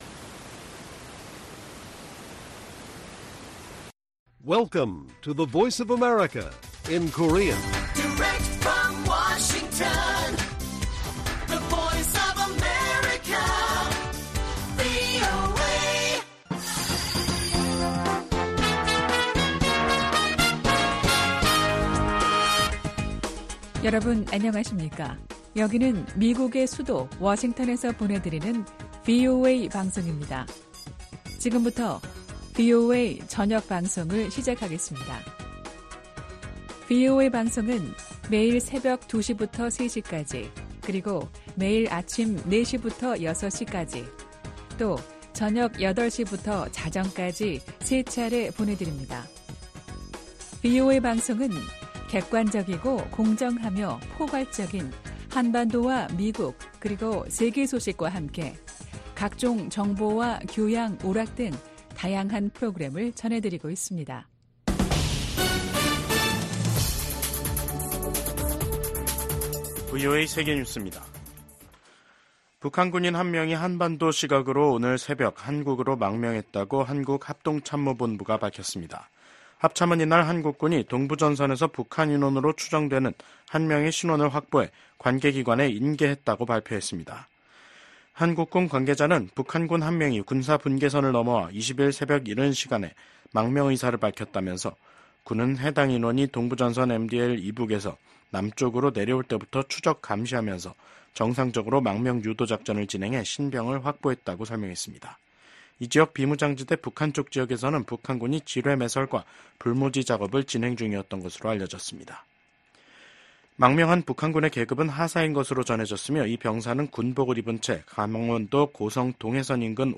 VOA 한국어 간판 뉴스 프로그램 '뉴스 투데이', 2024년 8월 20일 1부 방송입니다. 11월 대선을 앞두고 미국 민주당이 북한의 위협에 맞선 한국에 대해 변함없는 지지를 재확인하는 새 정강을 발표했습니다. 지난해 8월 캠프 데이비드에서 열린 미한일 3국 정상회의는 동북아에서 3국의 안보 협력을 한 단계 끌어올린 대표적 외교 성과라고 미 전문가들이 평가했습니다. 북한 군 병사 한 명이 20일 새벽 동부전선 군사분계선을 넘어 한국으로 망명했습니다.